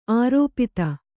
♪ ārōpita